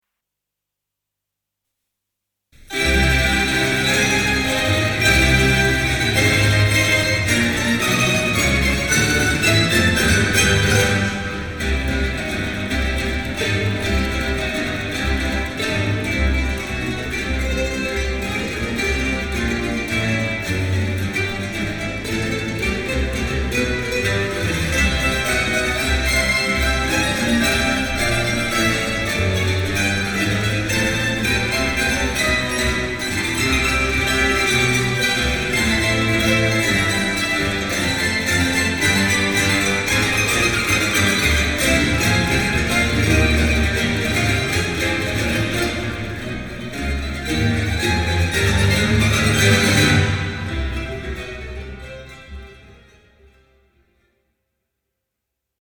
Mandolin Reference CD - No.11